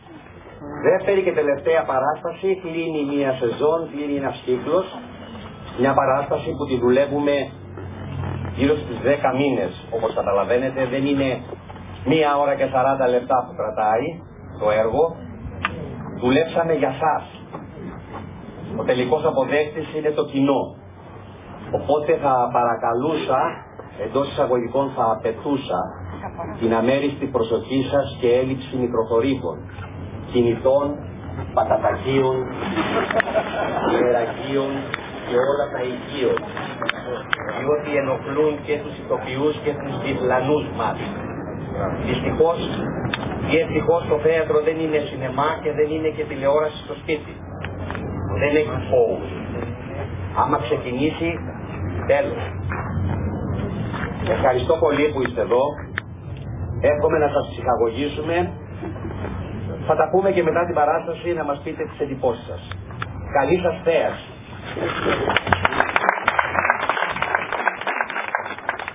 Η ερασιτεχνική θεατρική ομάδα του Πνευματικού-Νεανικού Κέντρου
Η παράσταση ανέβηκε στο Κηποθέατρο της “Πύλης Βηθλεέμ” των Ενετικών Τειχών στον Δήμο Ηρακλείου στις 2 και 3 Αυγούστου 2025.